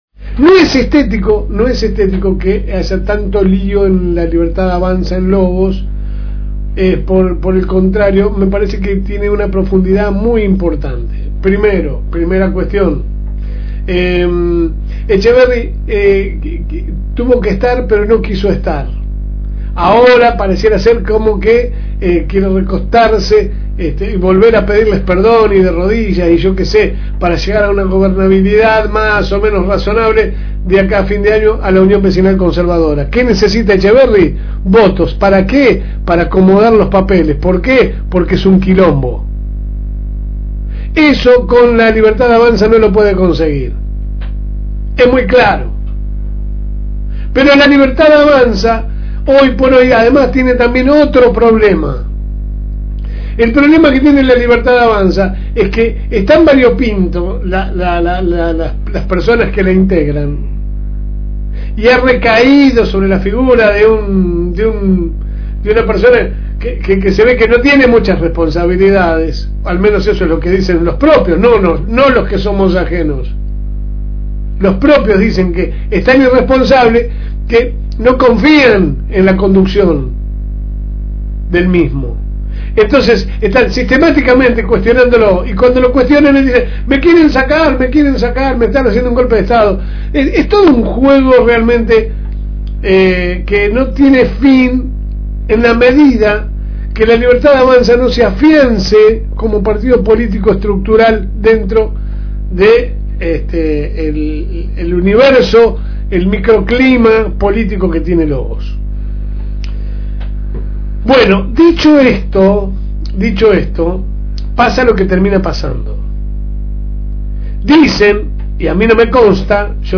Que sale por el aire de la FM Reencuentro 102.9 de lunes a viernes de 10 a 12 Hs